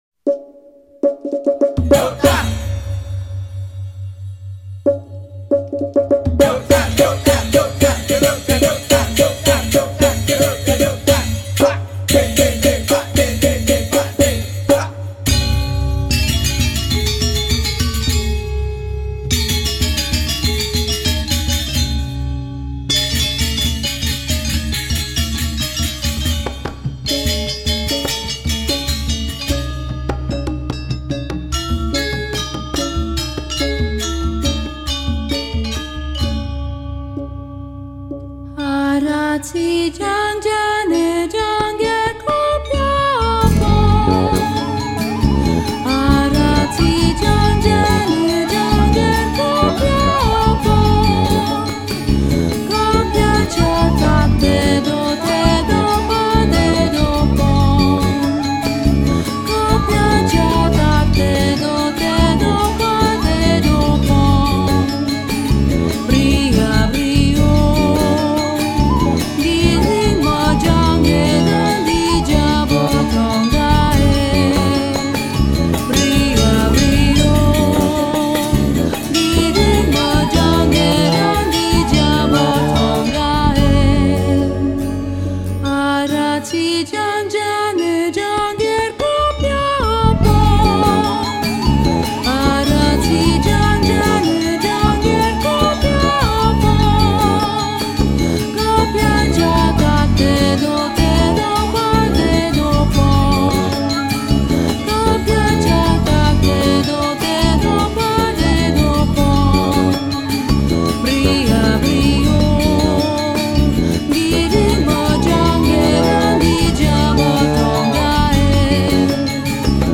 voice
guitars
bass
l’orchestre balinais